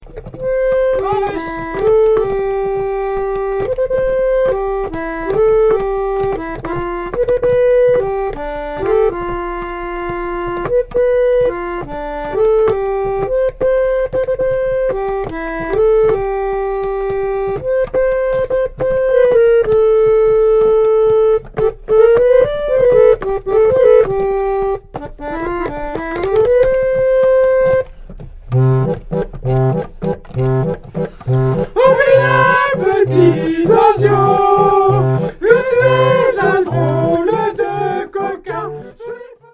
Le fameux guide-chant-orgue-barbare à pompe
en do majeur avec